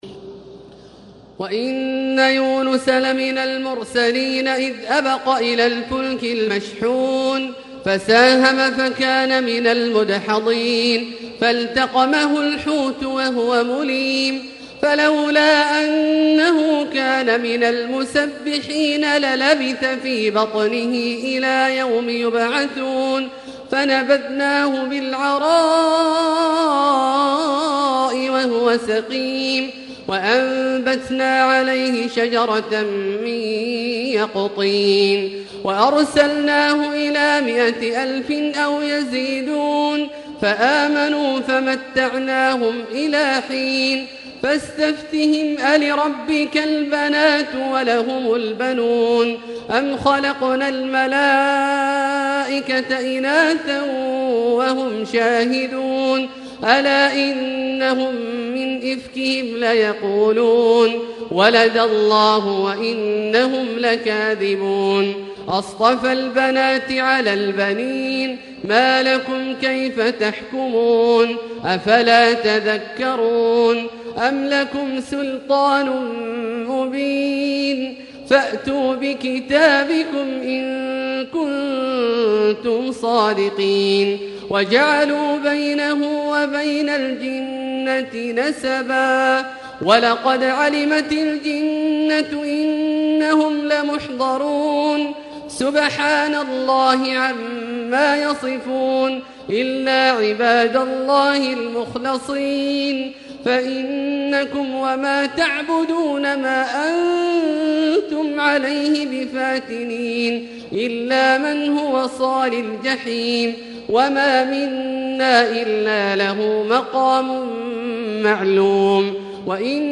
تراويح ليلة 22 رمضان 1440هـ من سور الصافات (139-182) وص و الزمر (1-31) Taraweeh 22 st night Ramadan 1440H from Surah As-Saaffaat and Saad and Az-Zumar > تراويح الحرم المكي عام 1440 🕋 > التراويح - تلاوات الحرمين